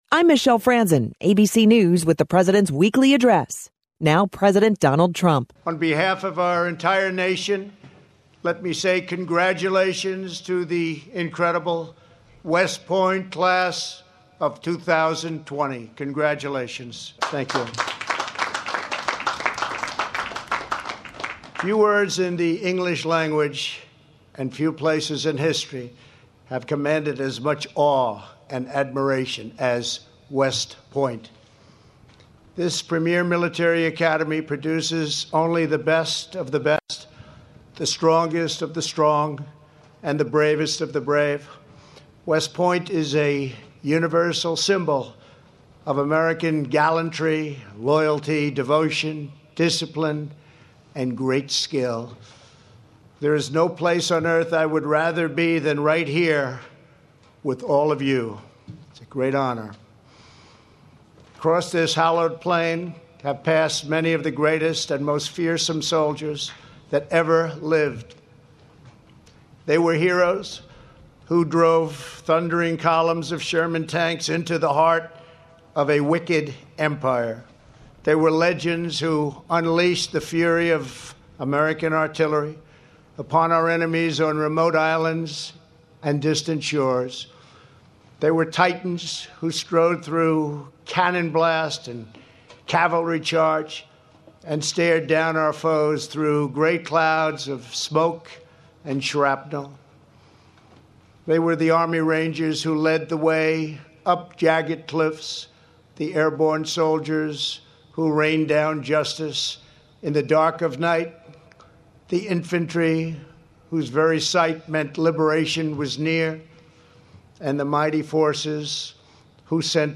On Saturday, President Donald Trump delivered remarks at the United States Military Academy, West Point Graduation Ceremony.
Here is his speech: